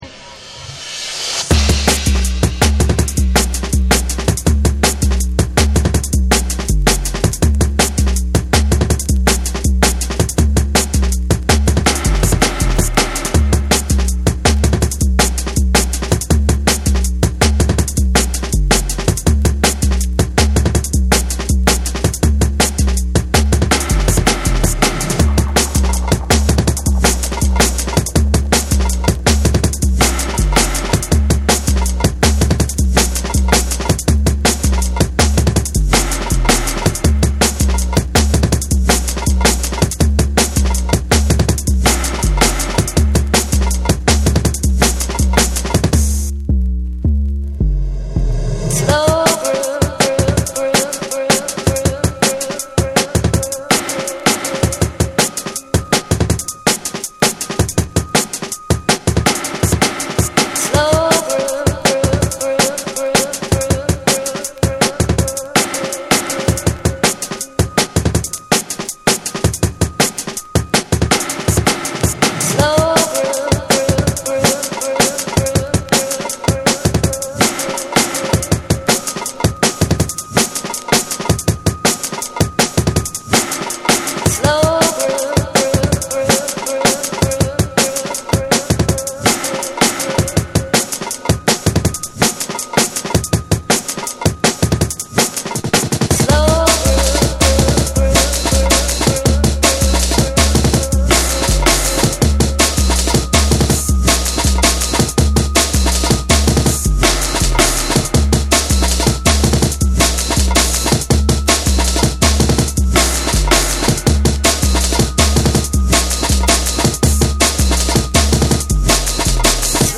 抜けのあるリズミカルなドラムンベースを披露する1(SAMPLE 1)。
BREAKBEATS